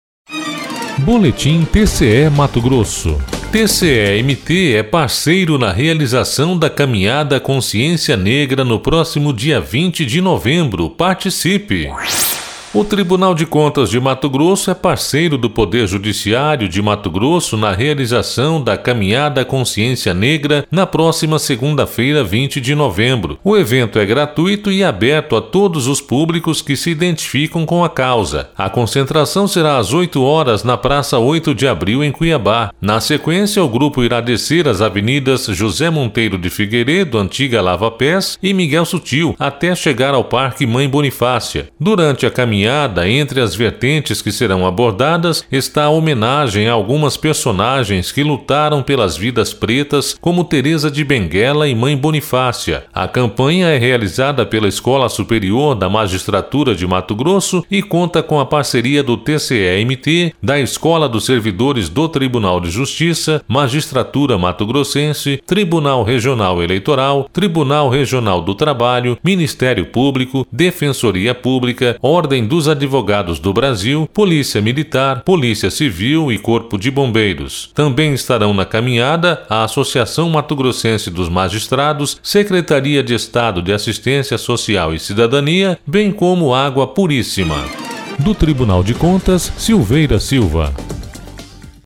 17 - Boletim TCE-MT é parceiro na realização da Caminhada Consciência Negra no próximo dia 20 de novembro; participe.mp3 (2.8 MB)